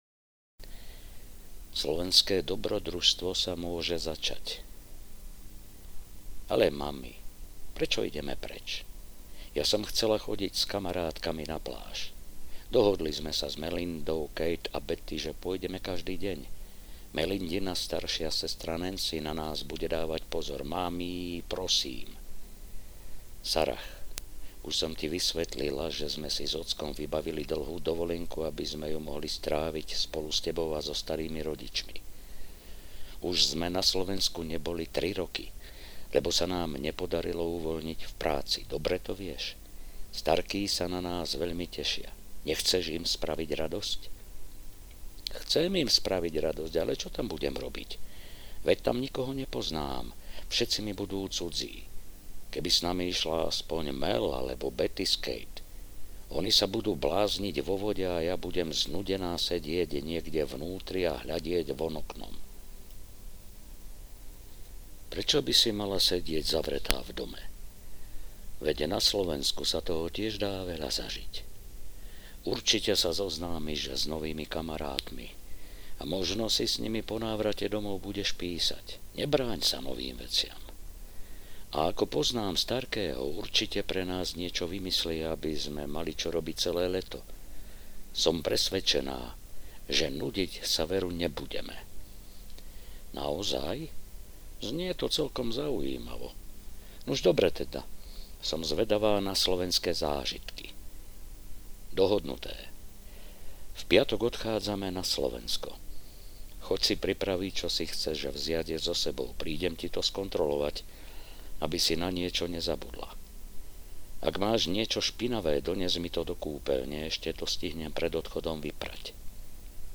Prázdniny s deduškom audiokniha
Ukázka z knihy